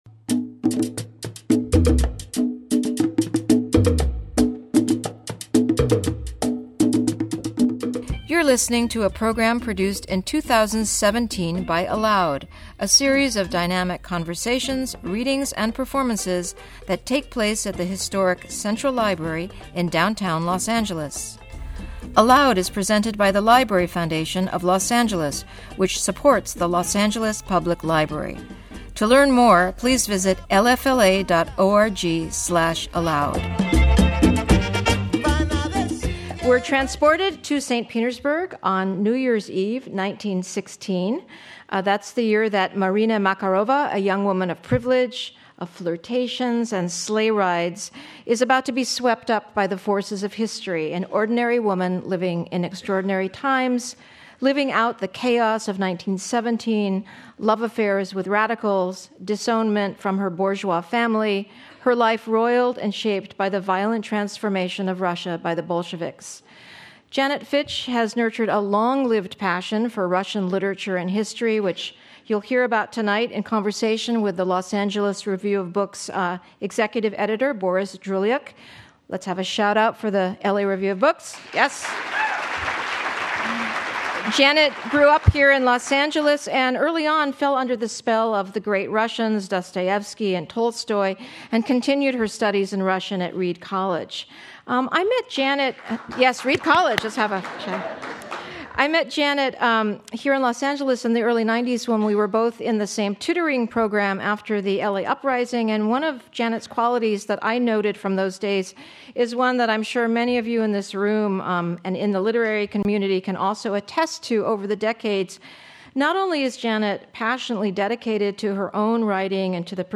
Janet Fitch In Conversation